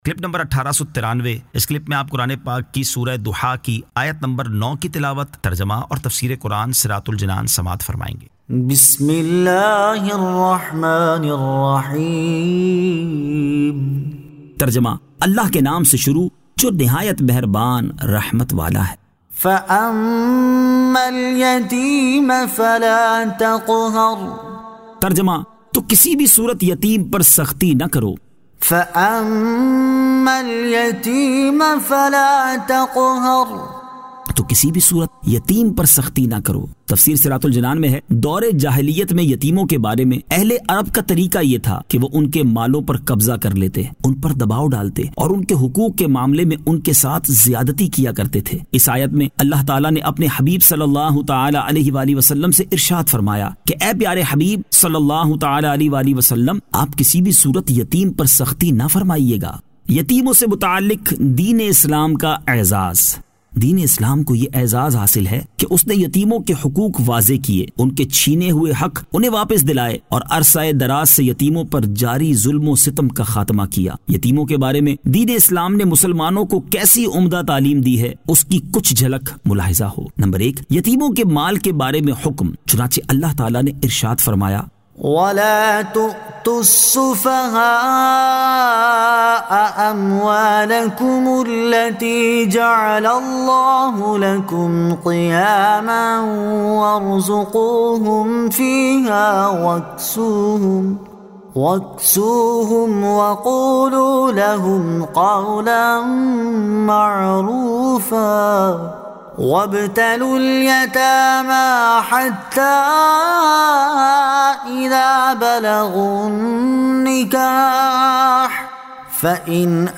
Surah Ad-Duhaa 09 To 09 Tilawat , Tarjama , Tafseer